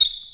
Squeek1c.mp3